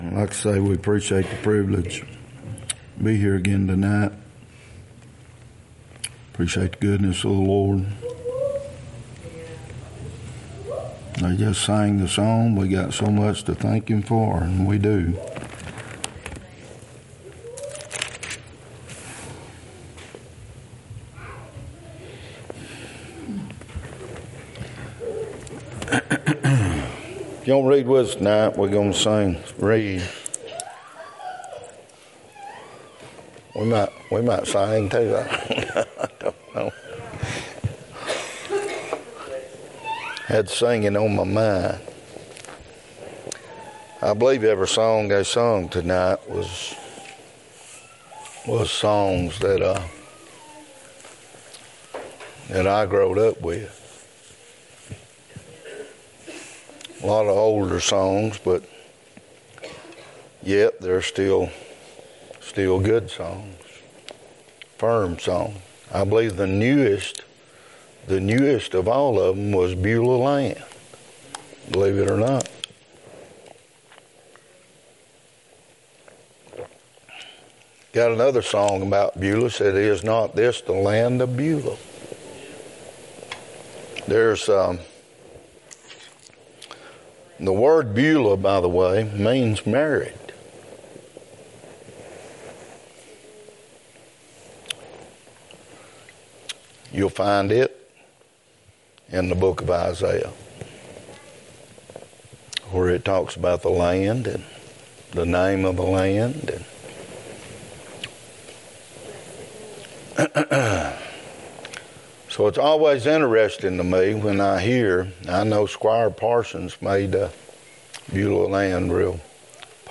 Isaiah 11:1-16 Romans 11:1-36 Service Type: Wednesday night Topics